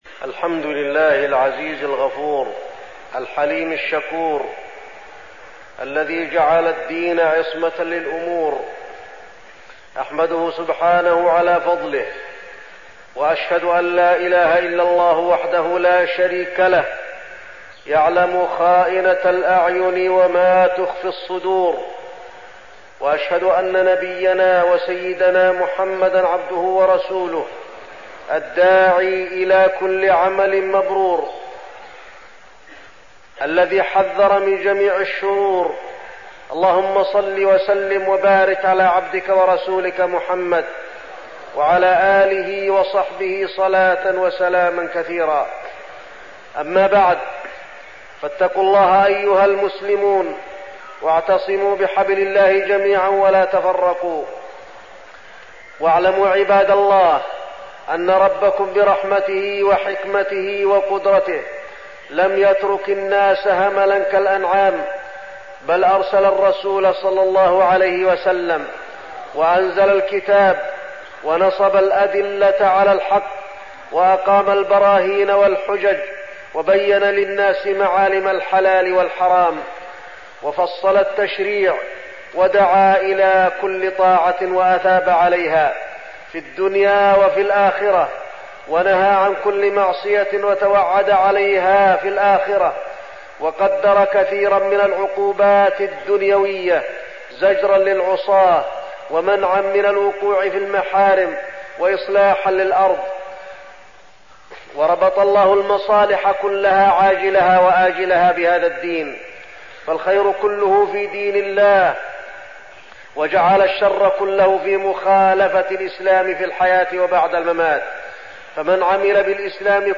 تاريخ النشر ٢٦ ذو القعدة ١٤١٧ هـ المكان: المسجد النبوي الشيخ: فضيلة الشيخ د. علي بن عبدالرحمن الحذيفي فضيلة الشيخ د. علي بن عبدالرحمن الحذيفي اجتناب المعاصي